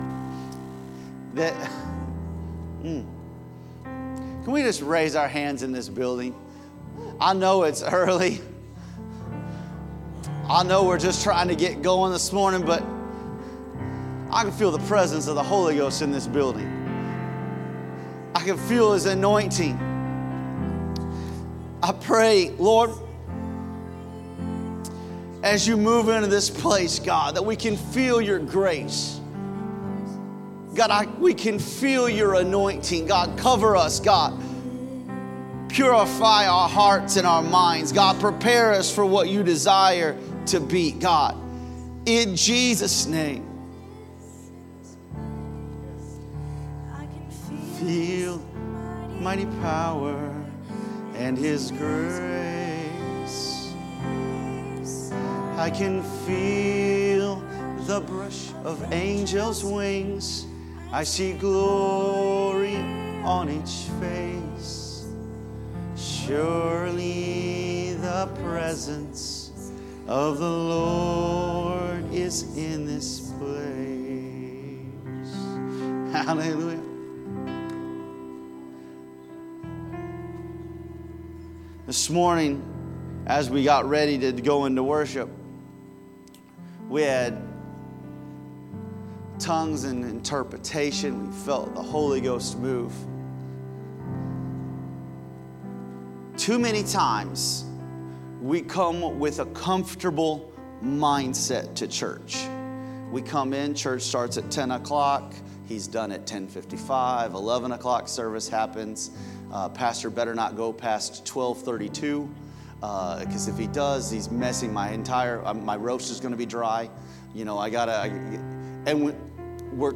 Sunday Service Without the Spirit There is No Fruit